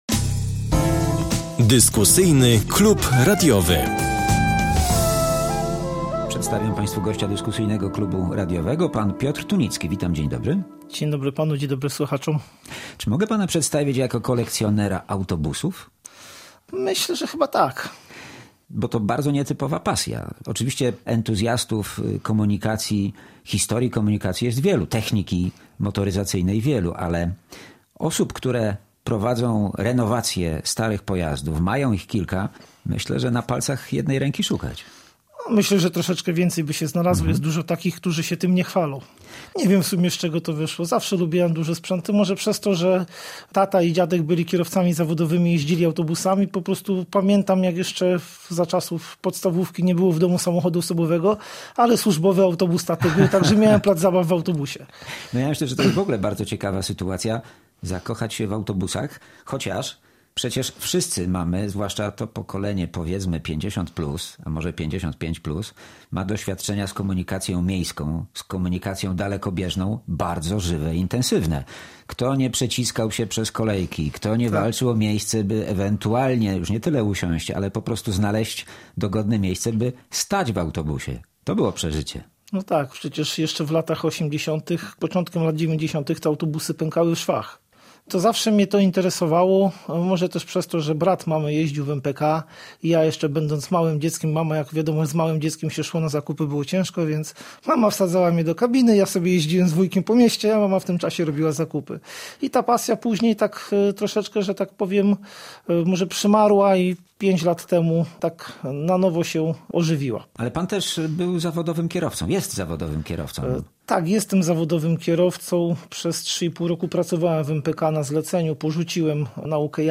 W Dyskusyjnym Klubie Radiowym spotkanie z pasjonatem motoryzacji, a dokładniej rzecz ujmując – z entuzjastą zabytkowych autobusów.